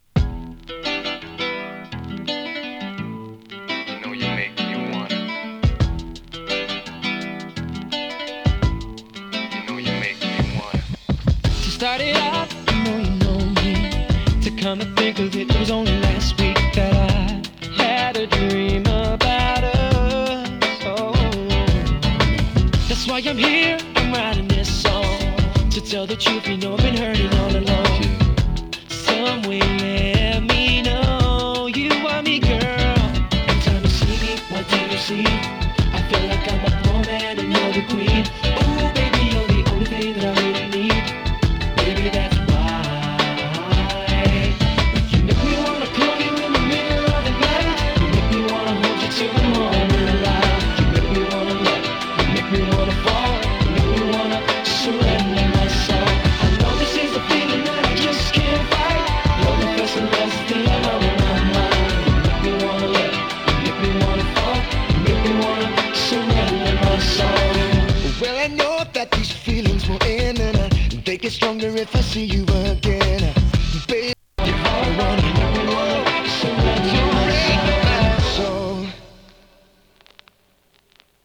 切ないメロディーが胸に沁みる哀愁ミディアムR&B！ UKのイケメン・ボーカル・グループのヒット曲。